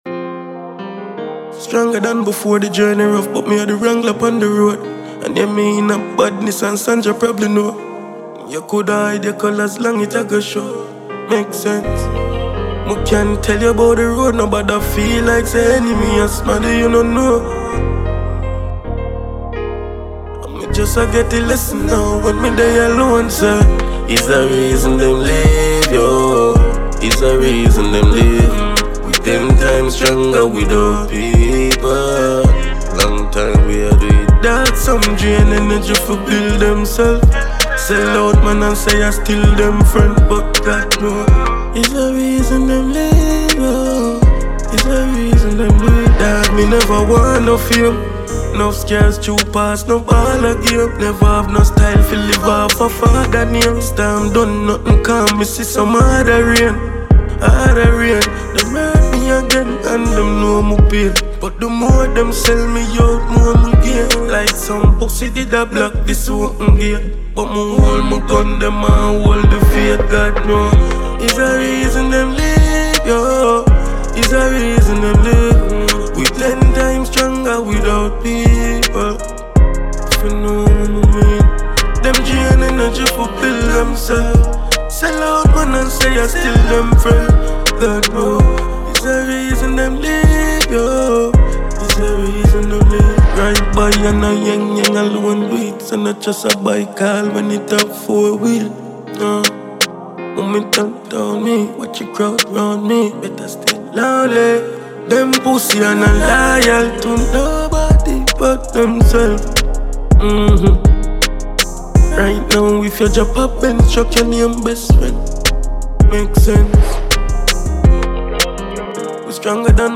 uplifting and motivational track
• Genre: Dancehall / Afrobeat